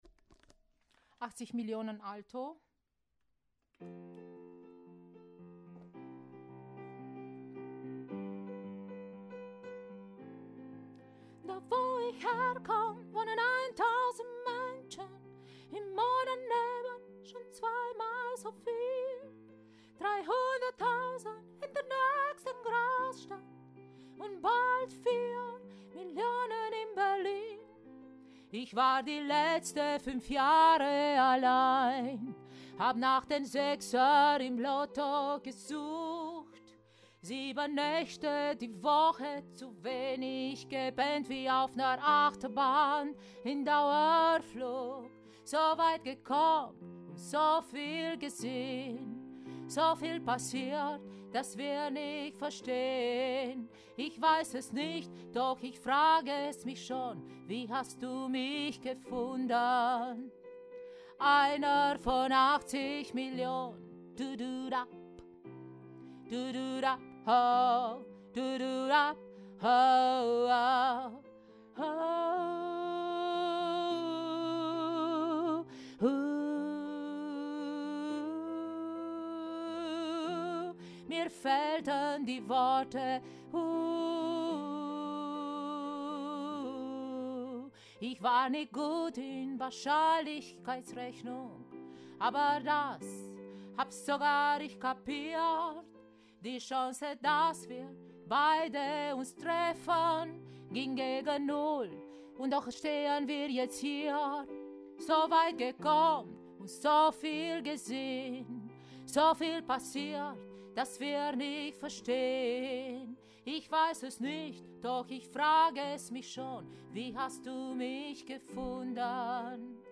80 Millionen – Alto
80Millionen-Alto.mp3